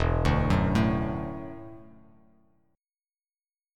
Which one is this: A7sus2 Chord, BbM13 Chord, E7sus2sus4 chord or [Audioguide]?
E7sus2sus4 chord